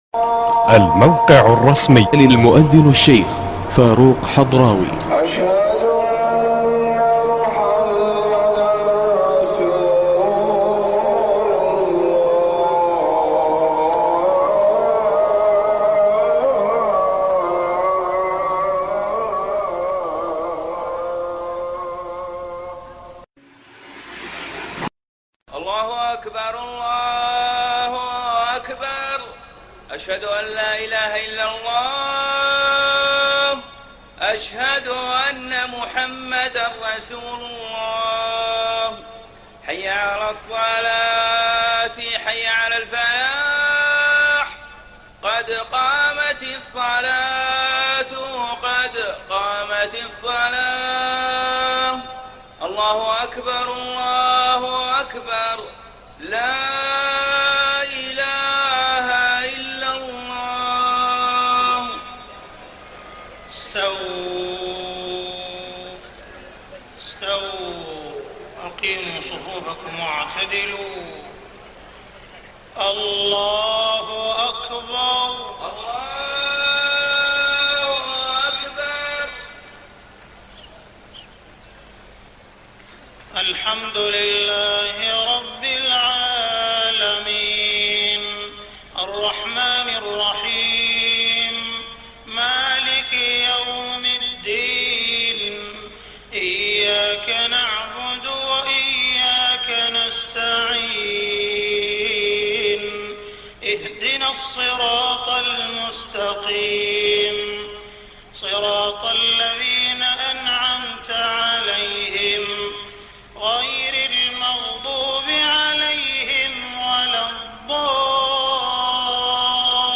صلاة المغرب 24 رمضان 1423هـ خواتيم سورتي الصافات و ص > 1423 🕋 > الفروض - تلاوات الحرمين